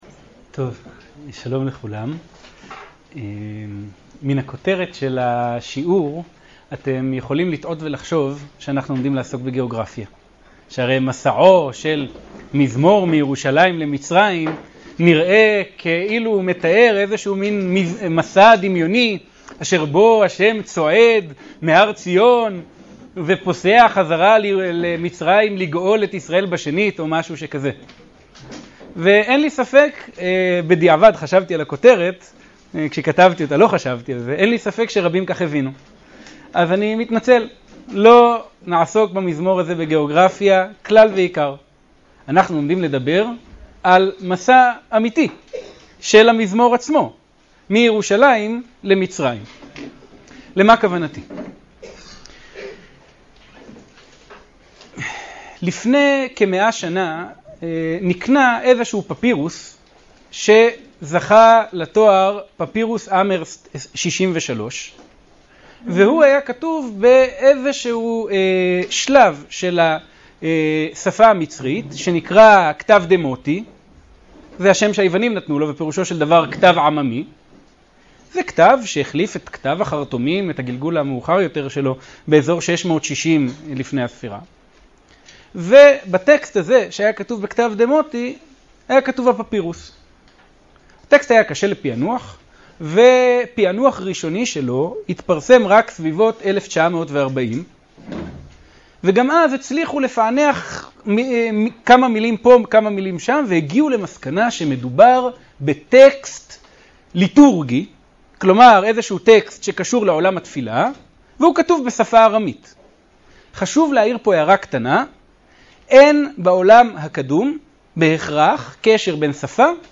השיעור באדיבות אתר התנ"ך וניתן במסגרת ימי העיון בתנ"ך של המכללה האקדמית הרצוג תש"ע